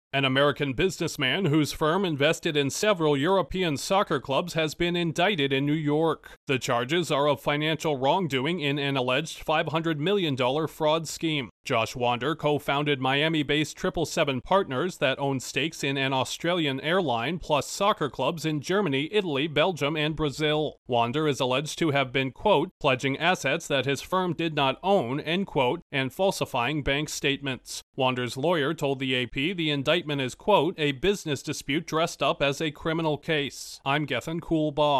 A U.S. businessman whose firm invested in soccer clubs has been indicted for alleged fraud of $500 million. Correspondent